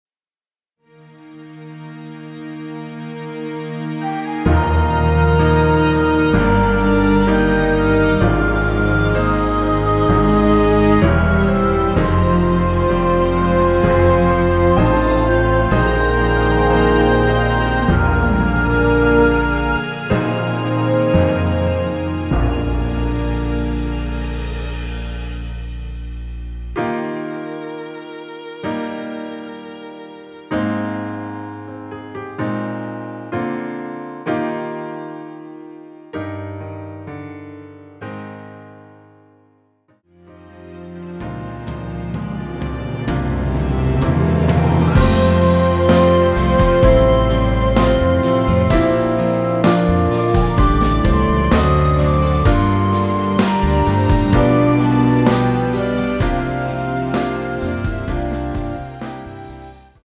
Eb
<전주 26초 정도> 원곡은 약 50초
앞부분30초, 뒷부분30초씩 편집해서 올려 드리고 있습니다.